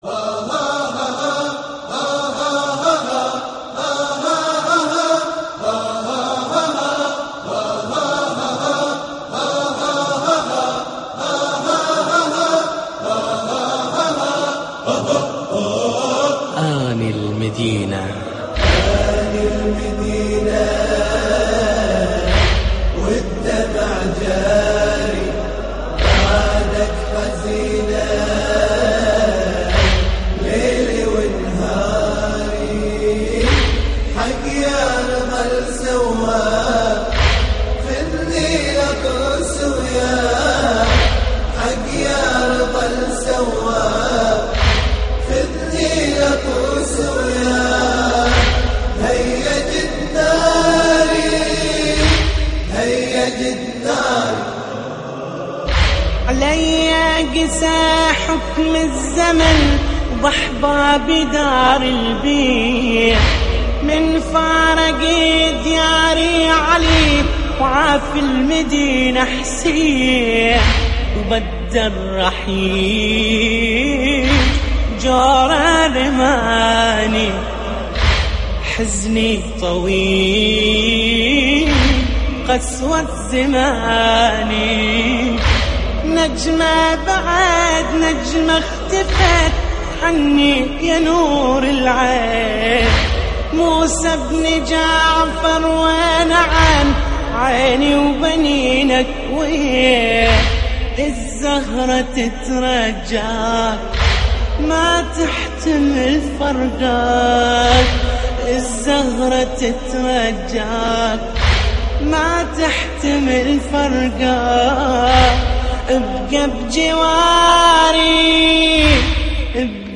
استديو